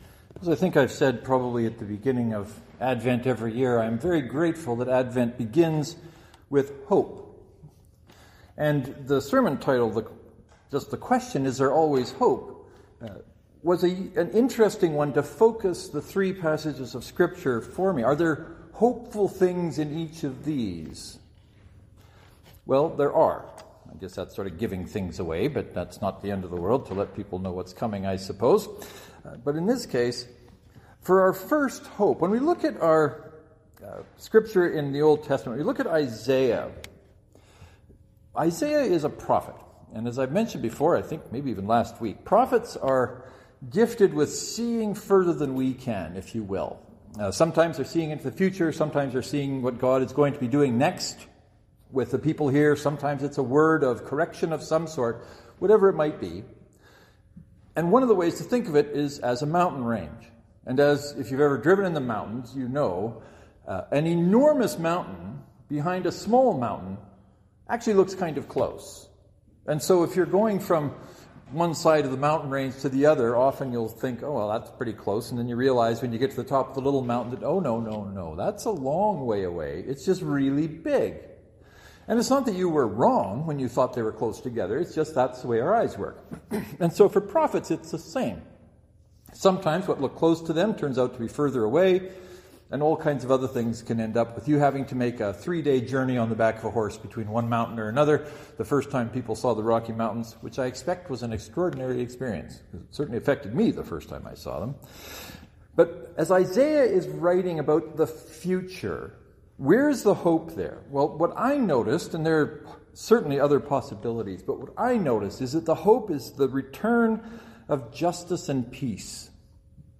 Hope is one of those things we sometimes forget about but is vital to our well-being. The sermon asks the question is there always hope?